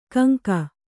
♪ kaŋka